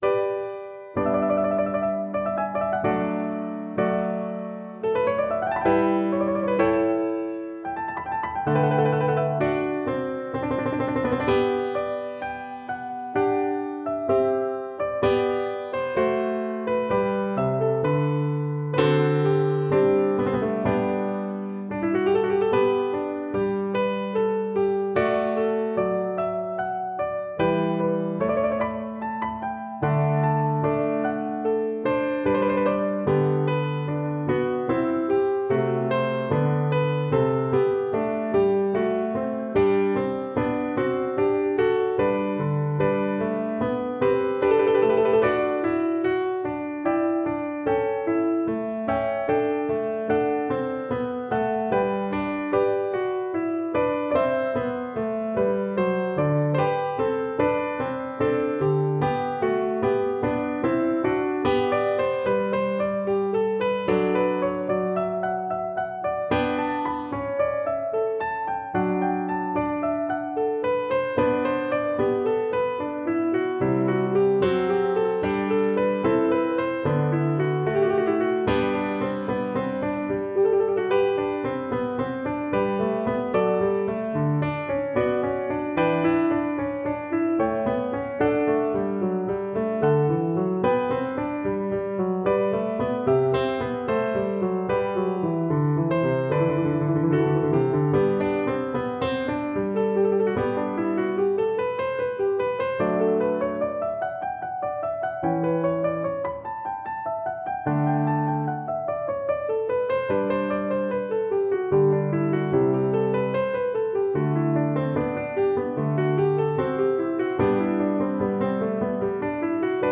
» 442Hz
• Violin & Viola Duet